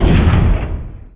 mega_bouncehard1.wav